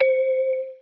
Piano - Harmonic.wav